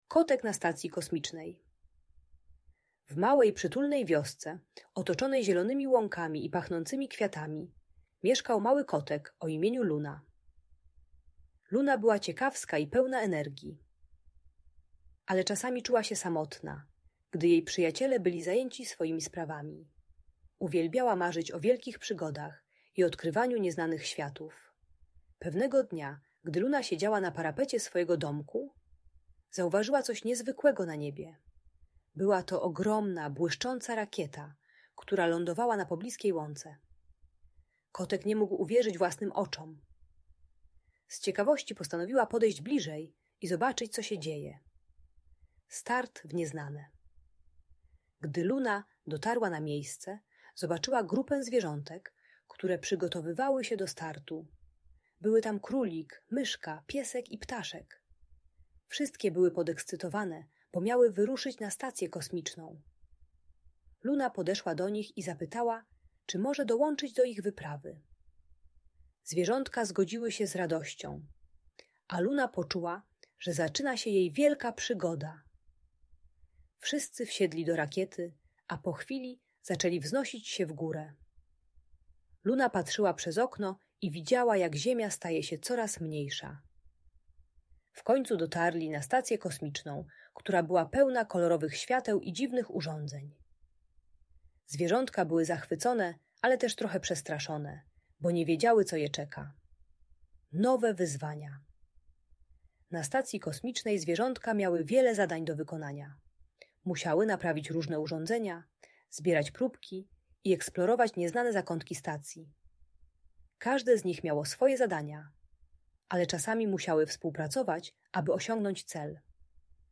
Przygoda kotka na stacji kosmicznej - Audiobajka dla dzieci